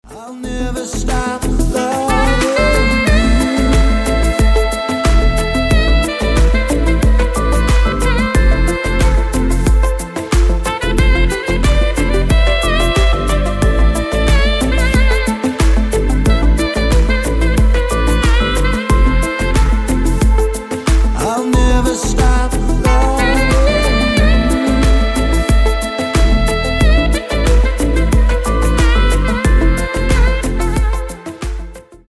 Кавер И Пародийные Рингтоны » # Рингтоны Без Слов